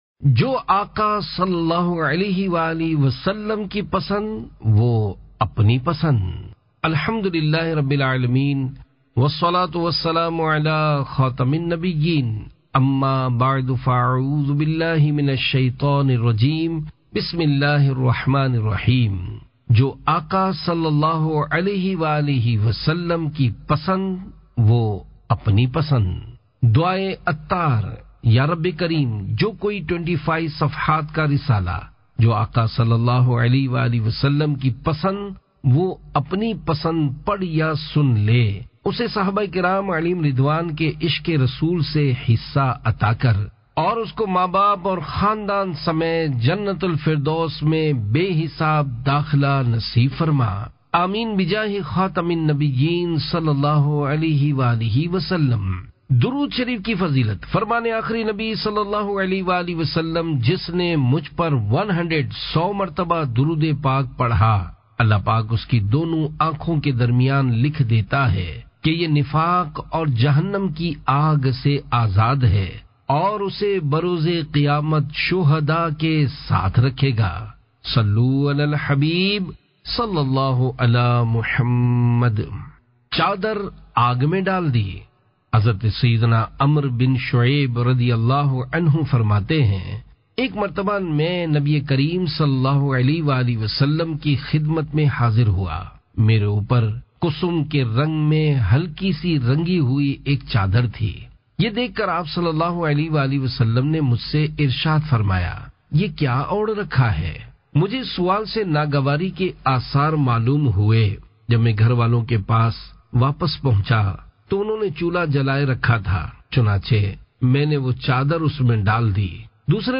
Audiobook - Jo Aaqa صلی اللہ تعالی علیہ وسلم Ki Pasand Wo Apni Pasand (Urdu)